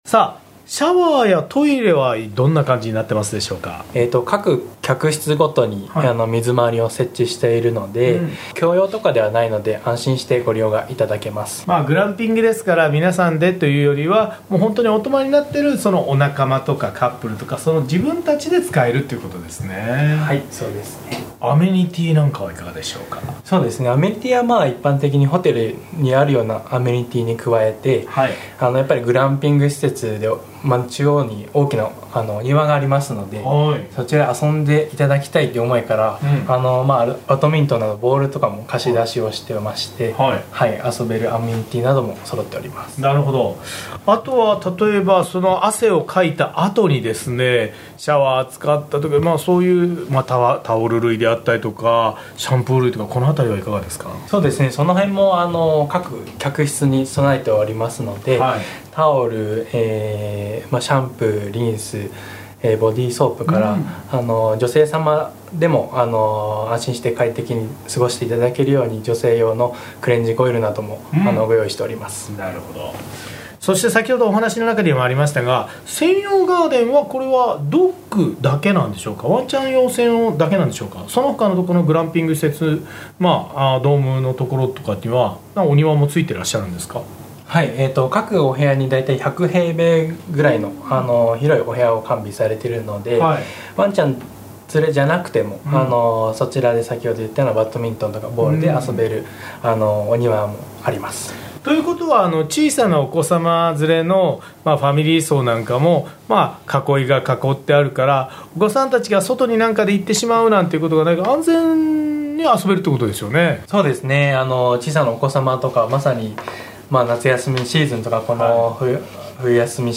毎週土曜午前11時から生放送。